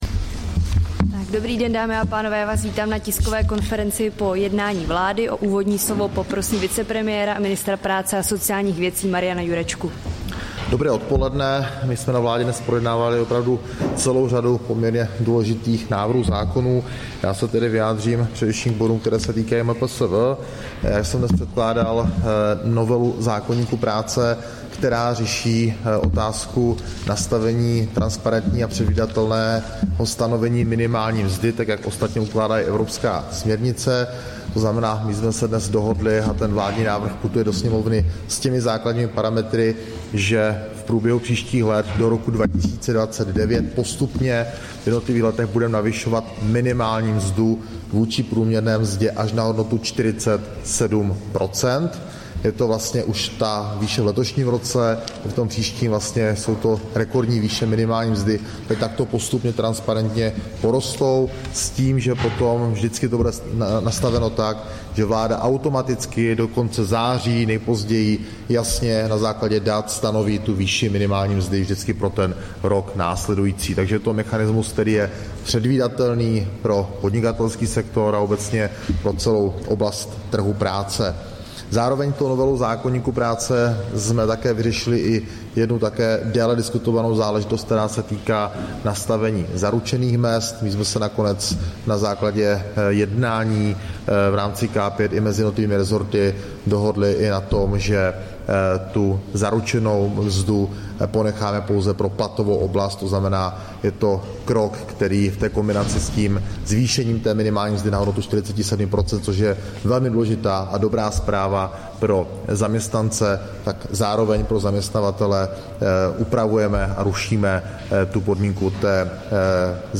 Tisková konference po jednání vlády, 20. března 2024